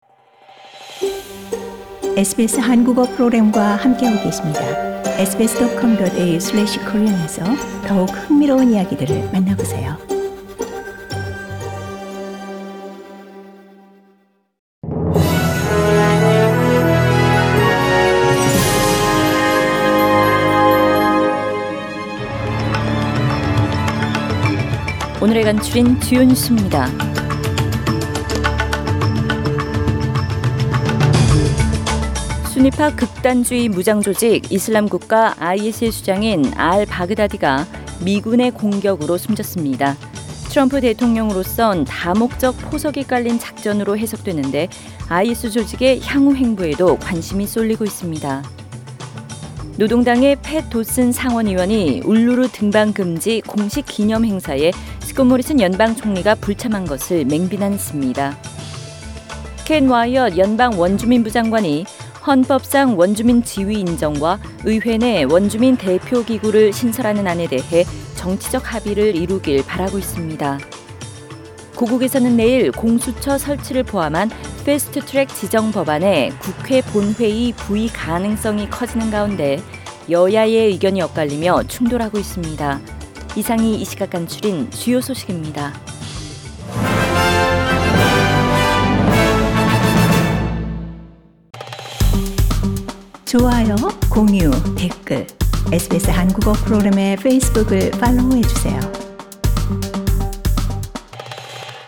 SBS 한국어 뉴스 간추린 주요 소식 – 10월 28일 월요일